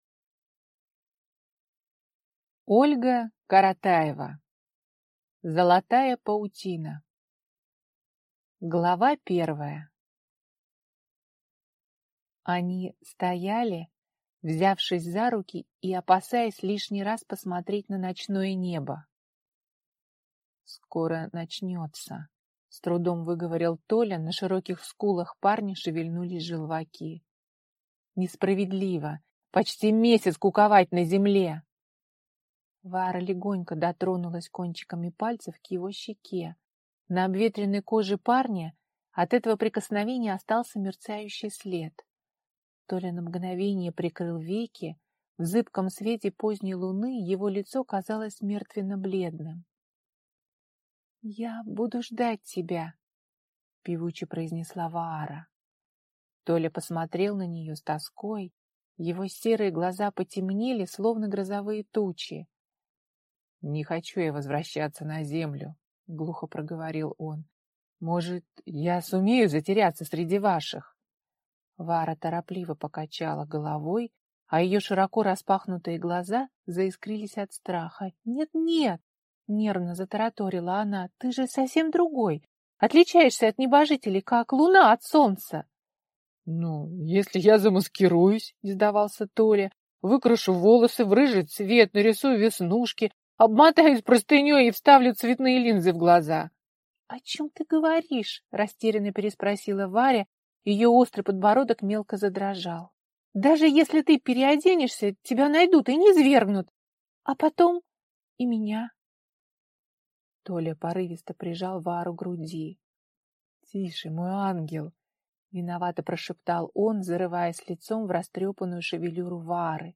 Аудиокнига Золотая паутина | Библиотека аудиокниг